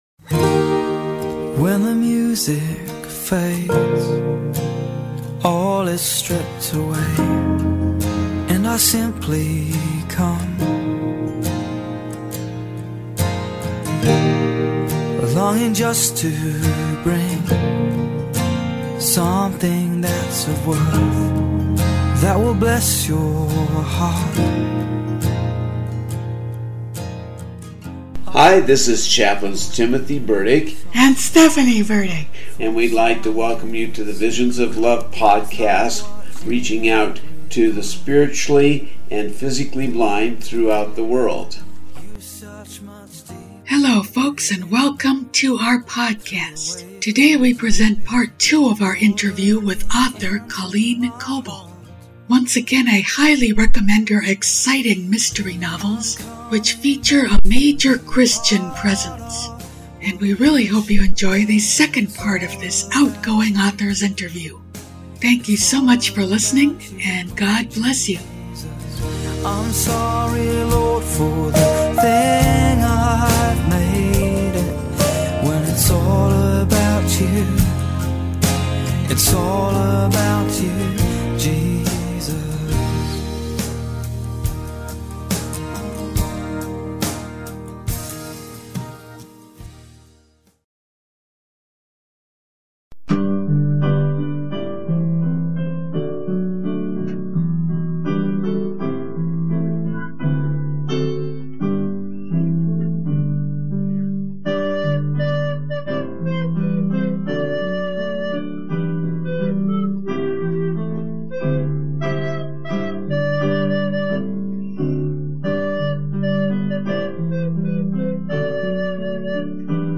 In this episode, we bring you Part 2 of our interview with Christian mystery novelist Colleen Coble.